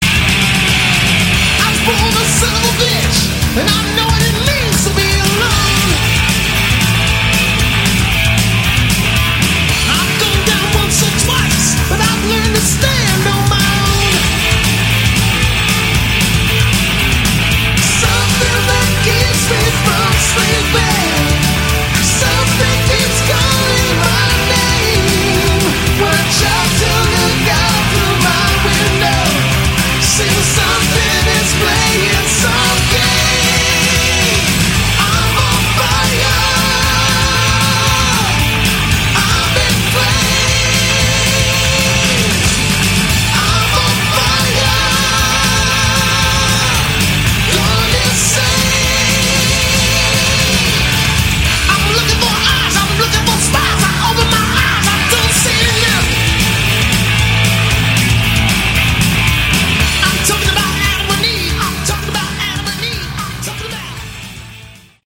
Category: Hard Rock
vocals, guitar, keyboards, bass
drums